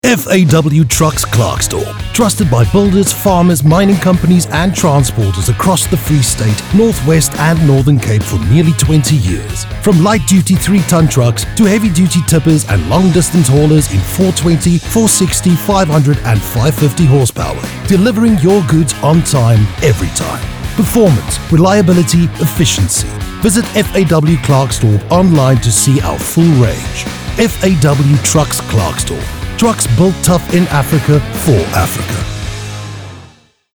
authoritative, Deep, raspy
Hard Sell